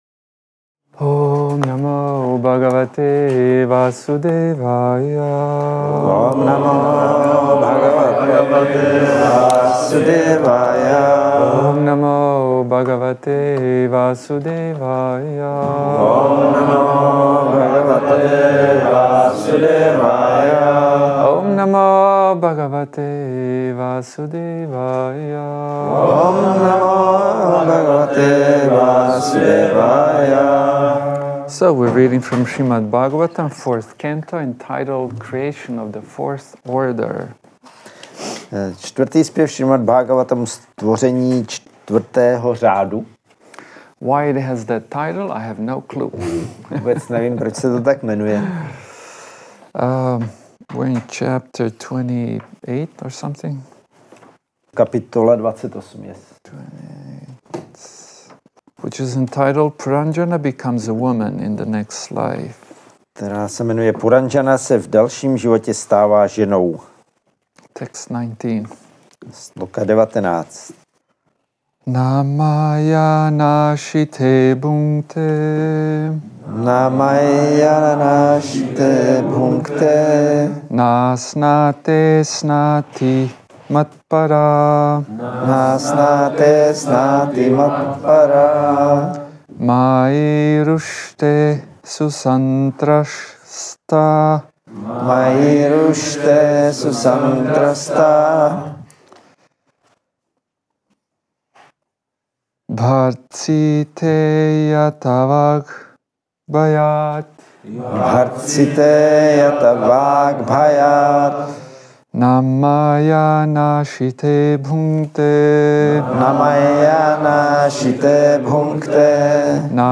Přednáška SB-4.28.19 – Krišnův dvůr – Šrí Šrí Nitái Navadvípačandra mandir